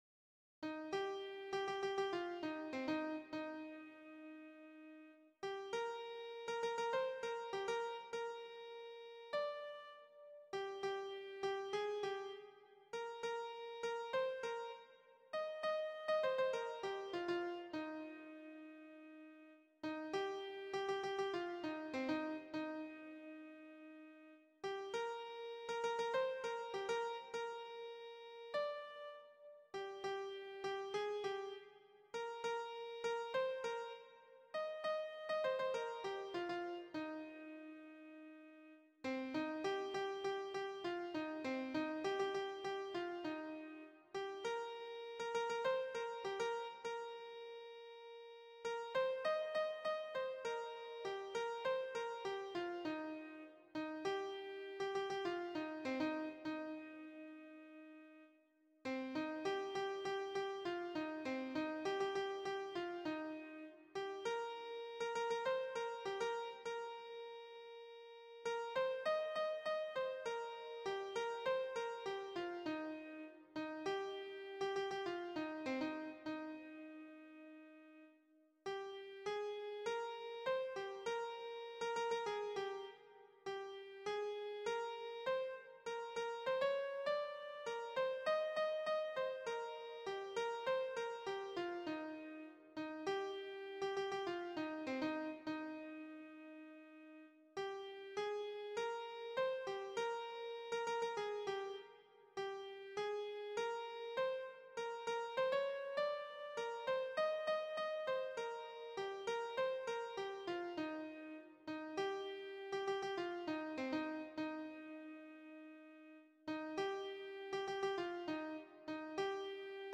Répétition SATB4 par voix
Soprano